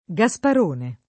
[ g a S par 1 ne ]